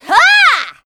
assassin_w_voc_attack03_e.ogg